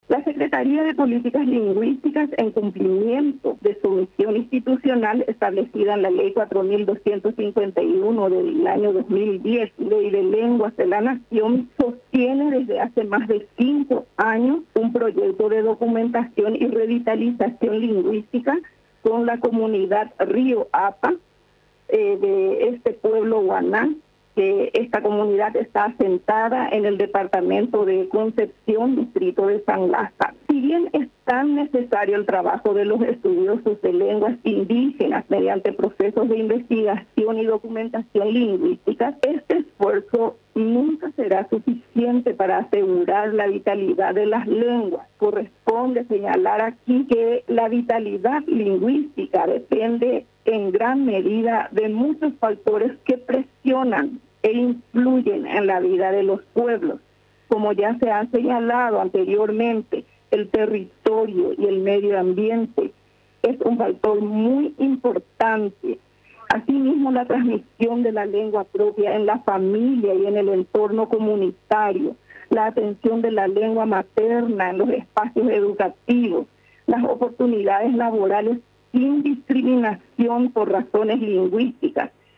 El Año Internacional de las Lenguas Indígenas fue lanzado este martes en un acto realizado en Palacio de Gobierno.
La ministra de la Secretaría de Políticas Lingüisticas Ladislaa Alcaraz, resaltó que este proyecto fue impulsado con la comunidad Río Apa del pueblo Guaná.